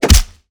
PUNCH_DESIGNED_HEAVY_86.wav